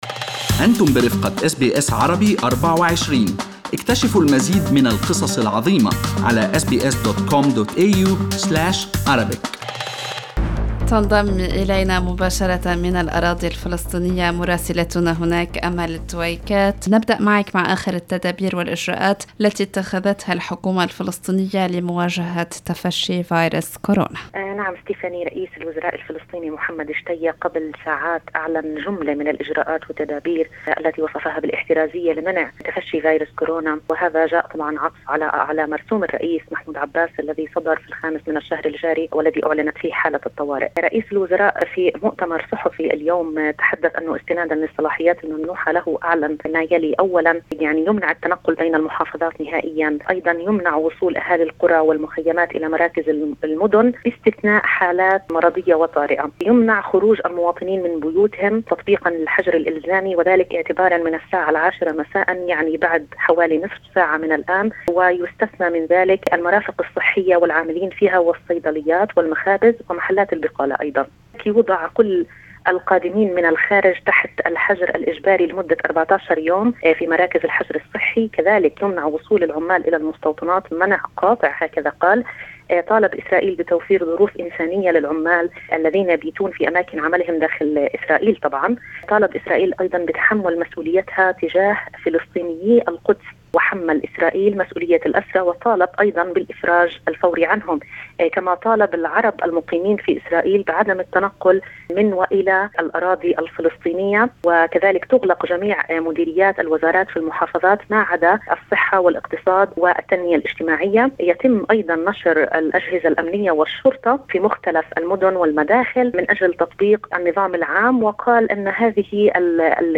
التقرير